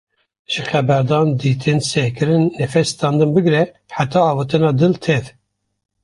Pronúnciase como (IPA)
/diːˈtɪn/